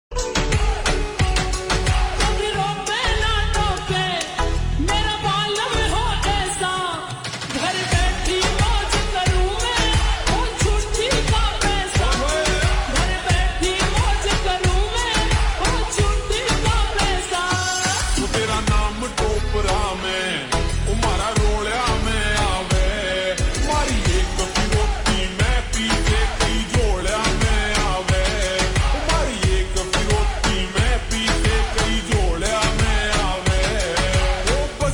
Haryanvi Songs
Slow Reverb Version
• Simple and Lofi sound
• Crisp and clear sound